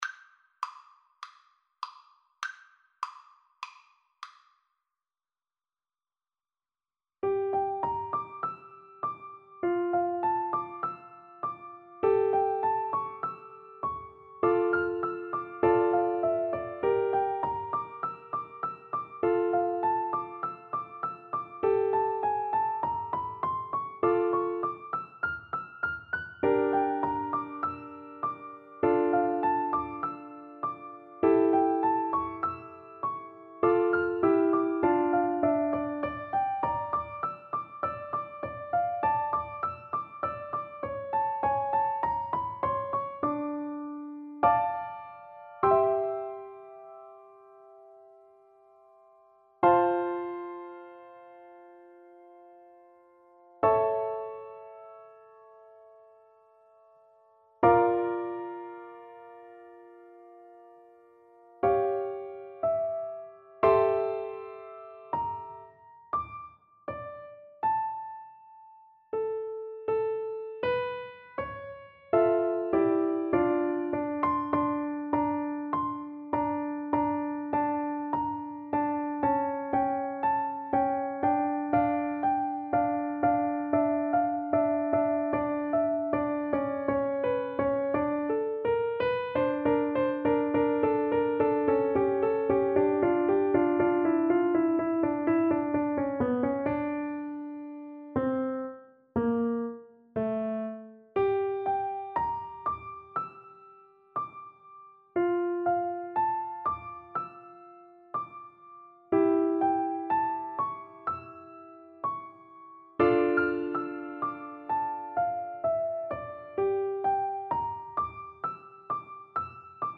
Play (or use space bar on your keyboard) Pause Music Playalong - Player 1 Accompaniment transpose reset tempo print settings full screen
D minor (Sounding Pitch) (View more D minor Music for Piano Duet )
Traditional (View more Traditional Piano Duet Music)